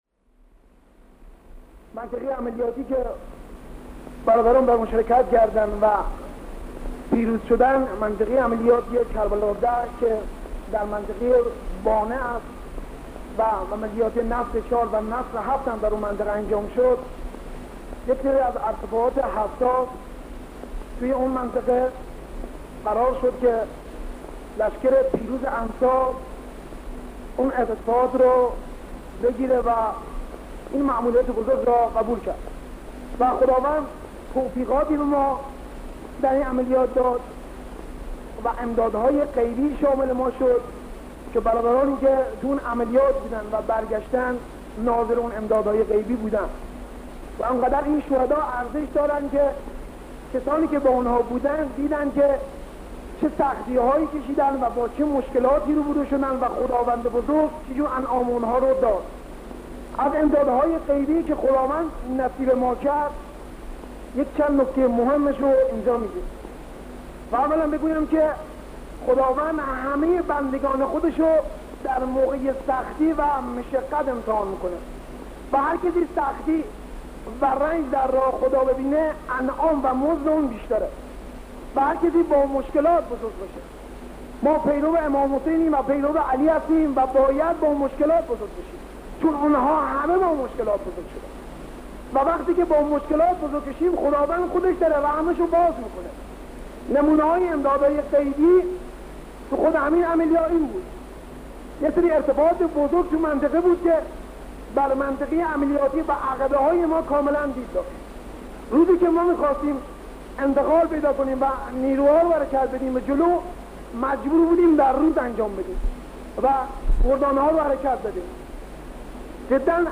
سخنرانی سردار شهید علی چیت سازیان